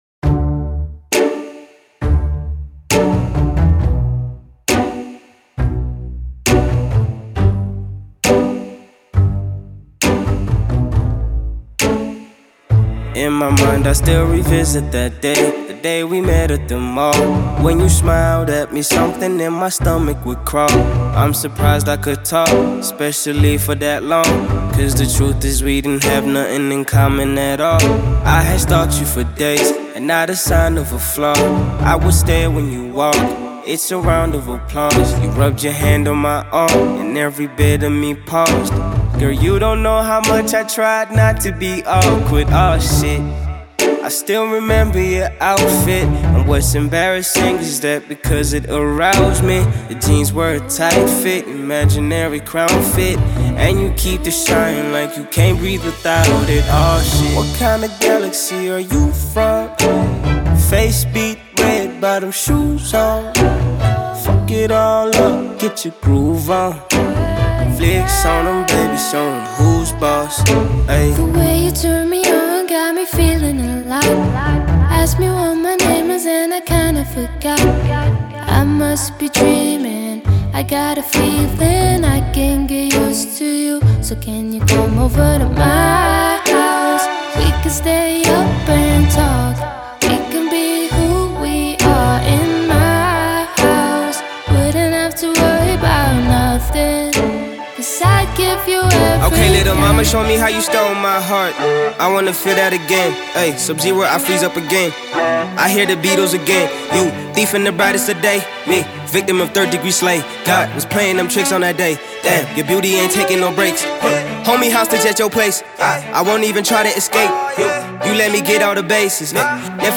electrifying and dazzling new tune
fierce vocals